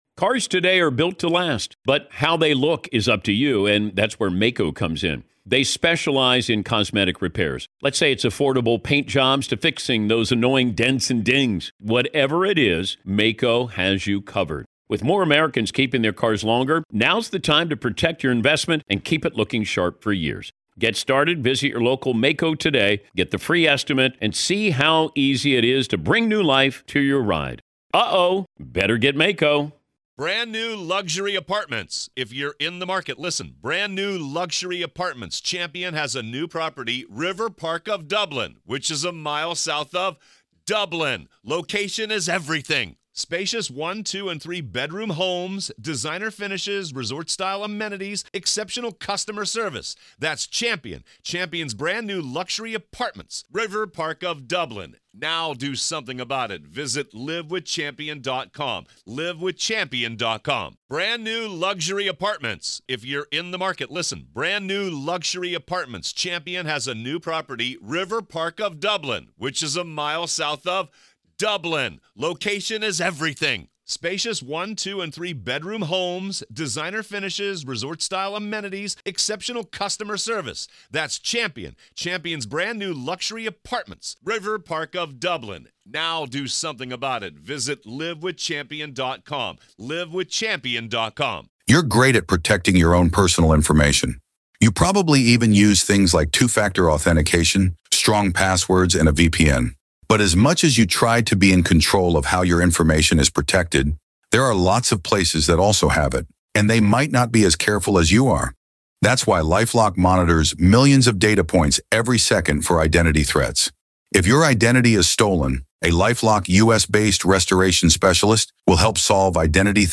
In-Depth Discussion